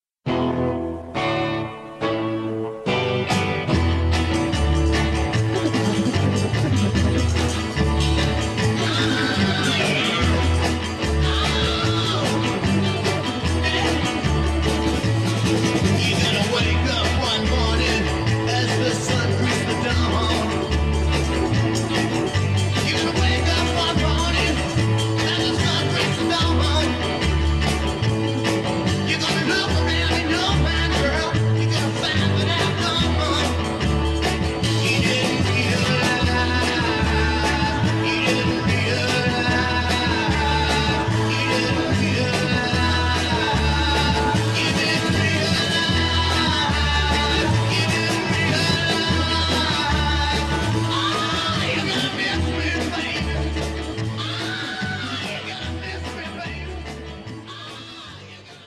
The music is weirdly pulsating, almost spooky, threatening.